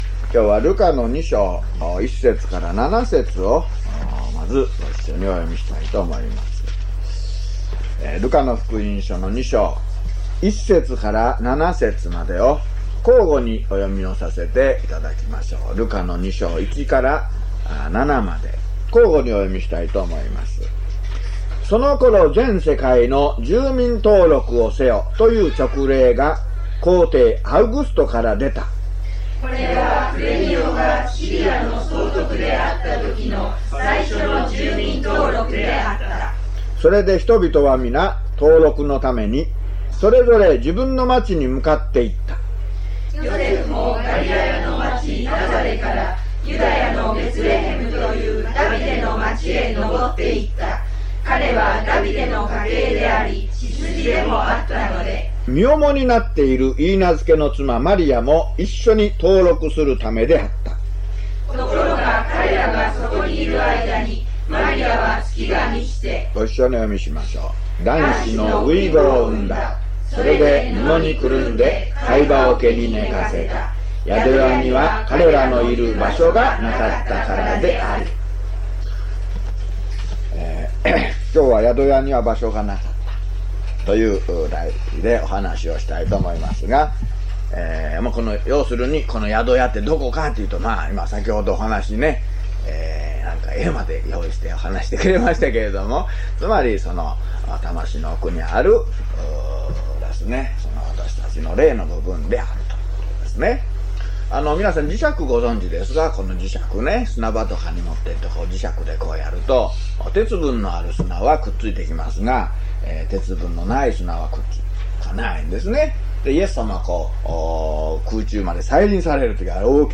luke009mono.mp3